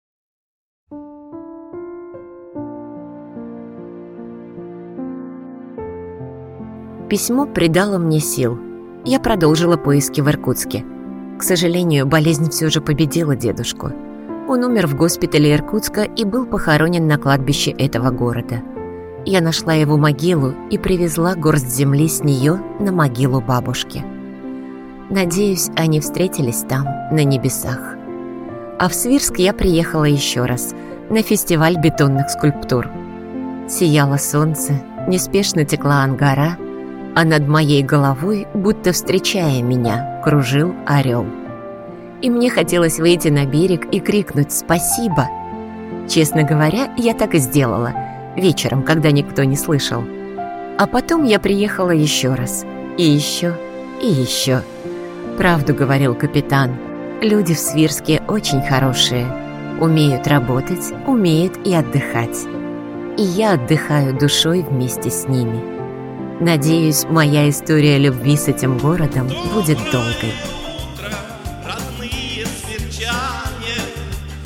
Жен, Закадровый текст/Средний
Микрофон SCARLETT CM25 Звуковая карта FOCUSRITE Дикторская кабина